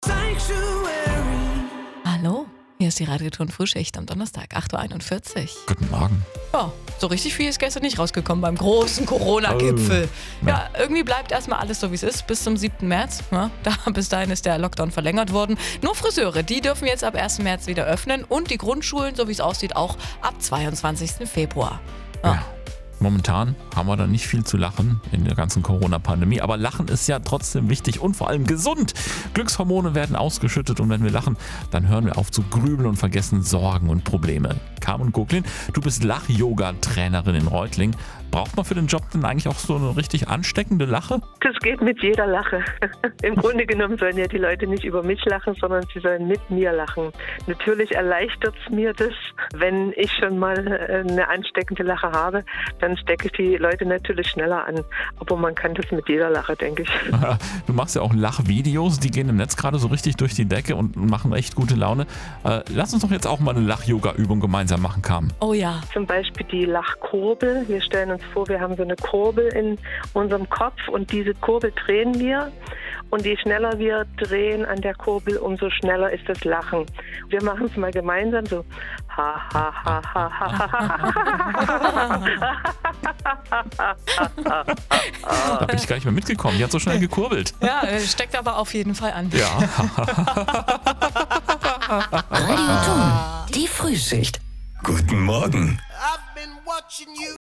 Interview mit Radio TON-Regional  (Februar 2021)